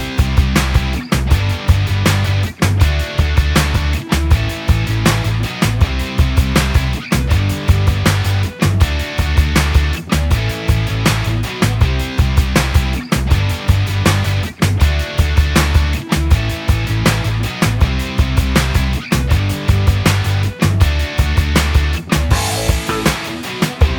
Minus Main Guitar Rock 3:42 Buy £1.50